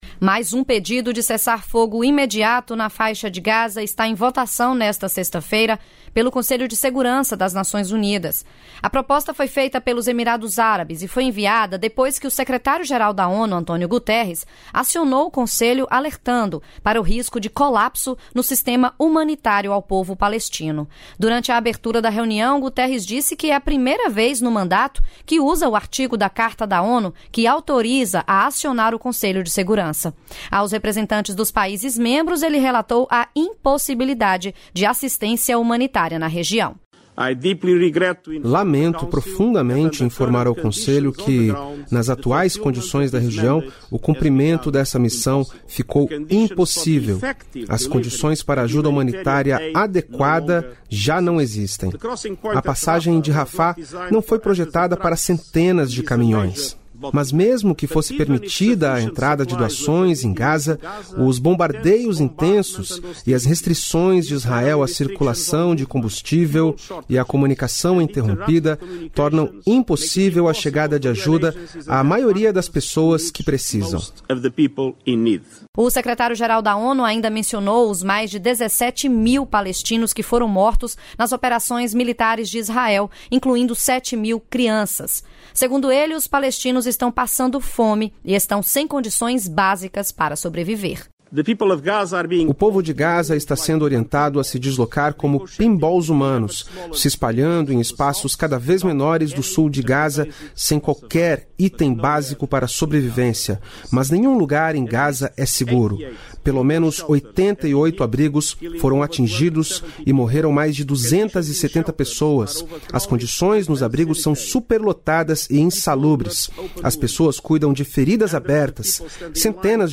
Repórter da Rádio Nacional ONU Faixa de Gaza Conflito no Oriente Médio sexta-feira